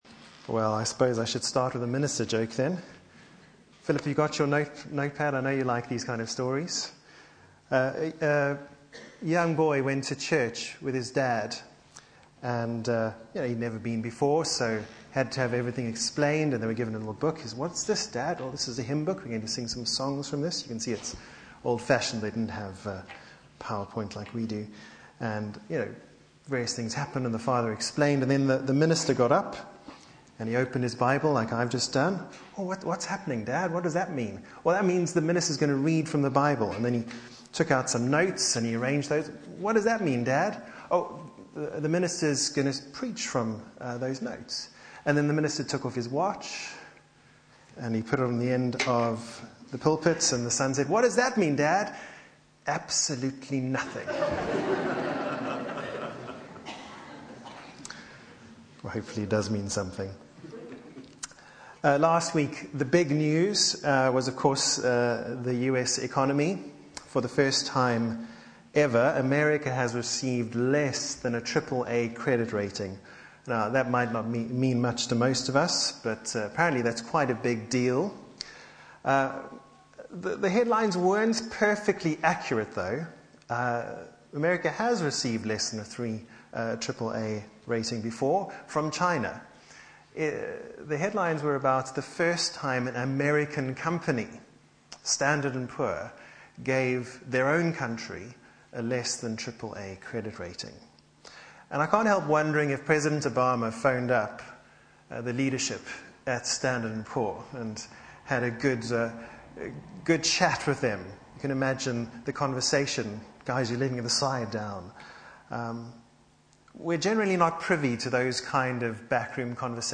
Media for Sunday Service on Sun 07th Aug 2011 11:00
Passage: 2 Timothy 4 Series: The Pastoral Epistles Theme: Sermon